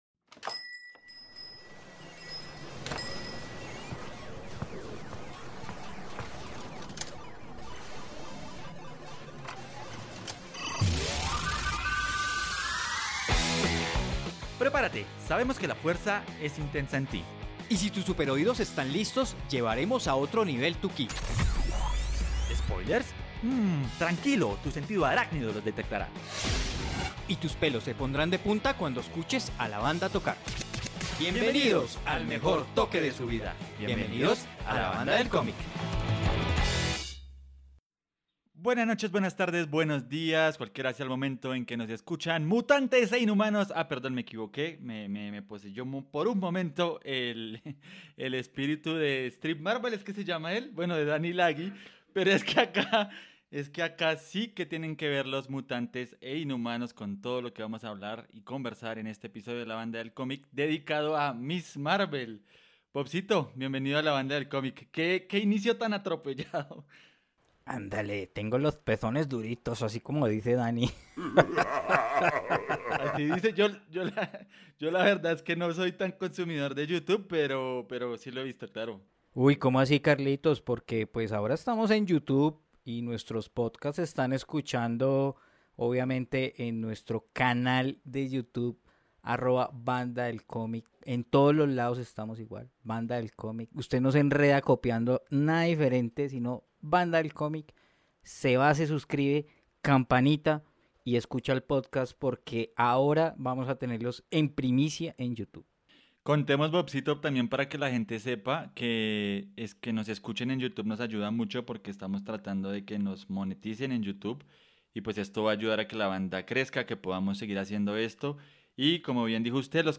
Acompáñanos en esta conversación sobre la nueva heroína del universo Marvel.